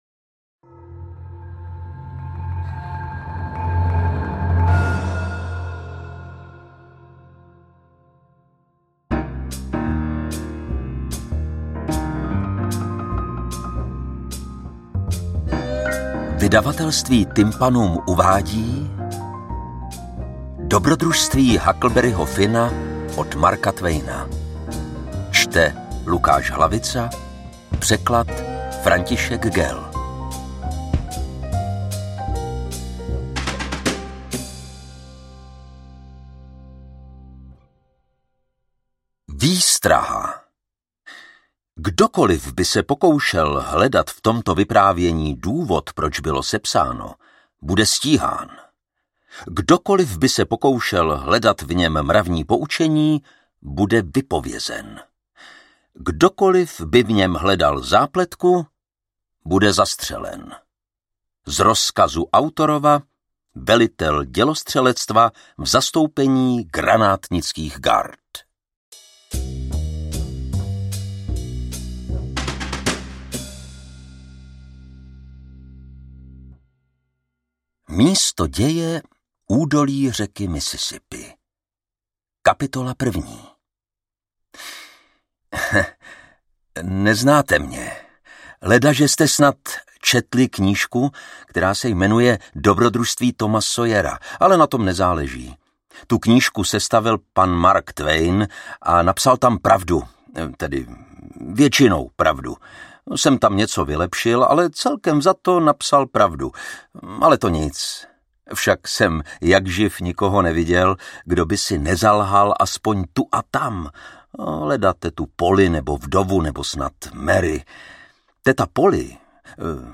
Interpret:  Lukáš Hlavica
AudioKniha ke stažení, 43 x mp3, délka 13 hod. 40 min., velikost 750,6 MB, česky